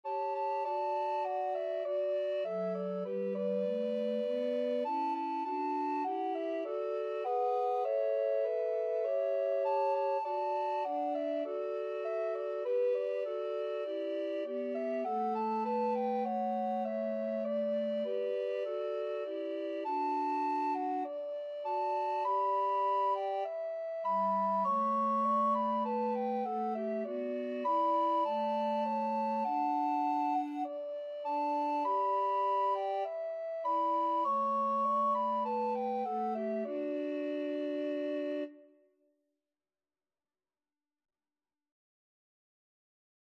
Alto RecorderTenor RecorderBass Recorder
4/4 (View more 4/4 Music)